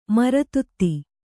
♪ mara tutti